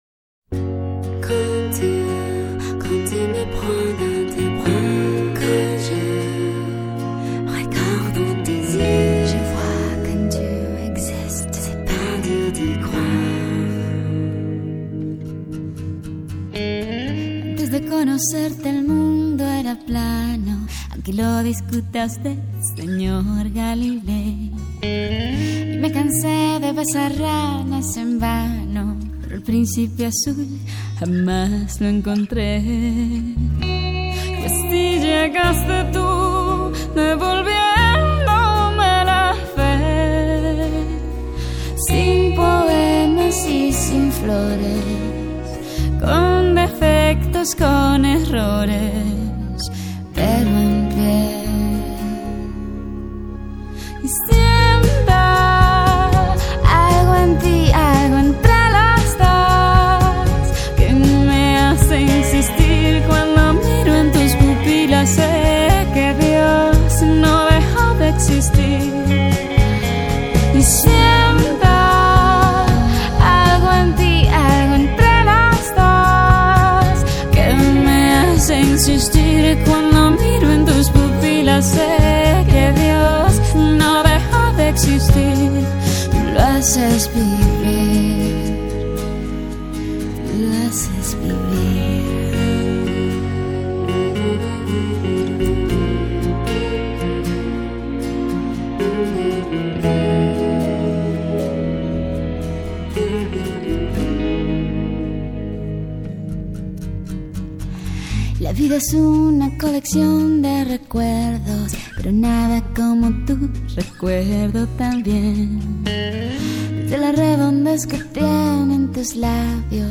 Latin Pop, Pop Rock, Reggaeton